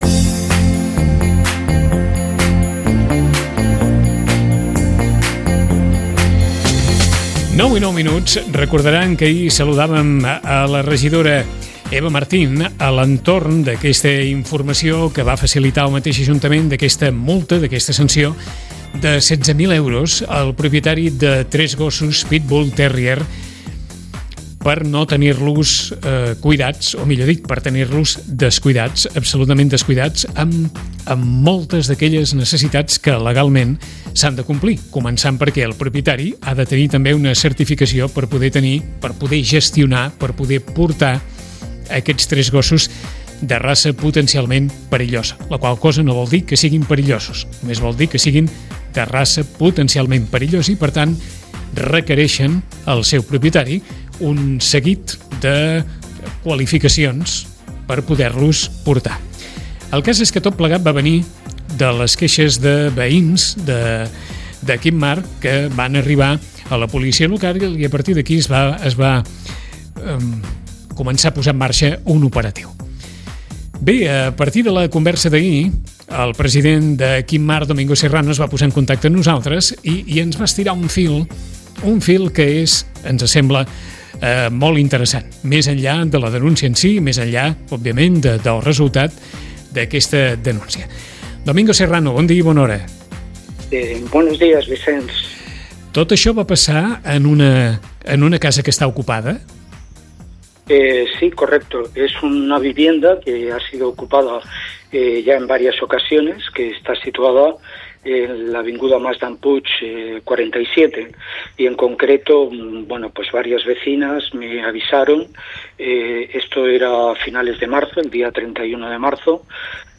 Ràdio Maricel. Emissora municipal de Sitges. 107.8FM. Escolta Sitges.